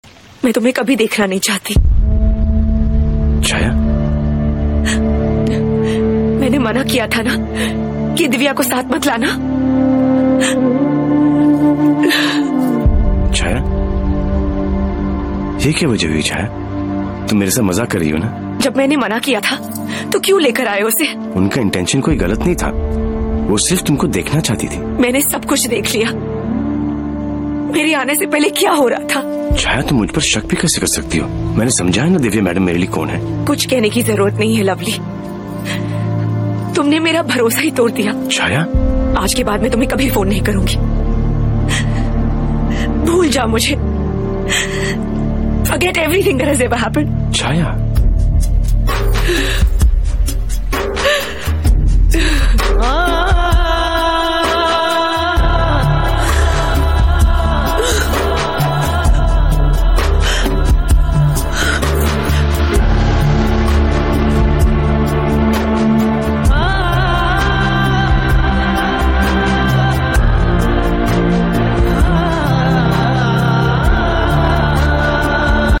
salman Khan best movie scene sound effects free download